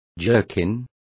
Complete with pronunciation of the translation of jerkins.